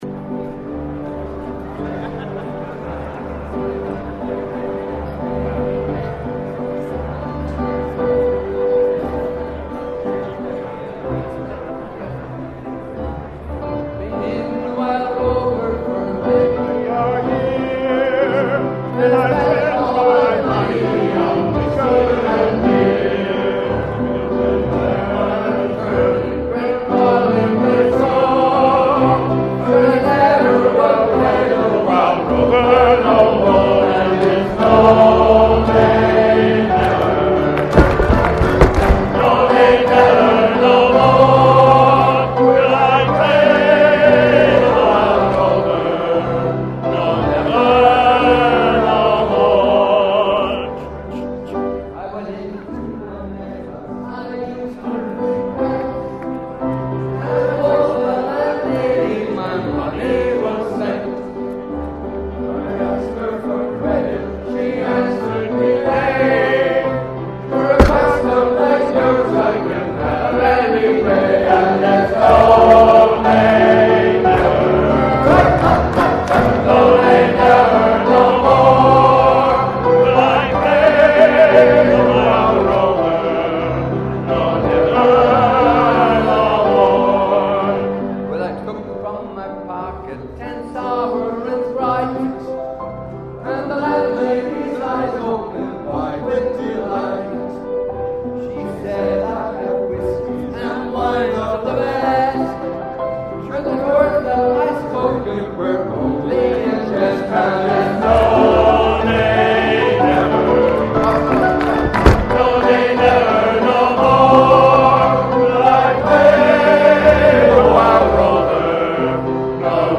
The company held an opening International Dinner featuring world class opera.
They joined in with the whole crowd for a couple of songs last night.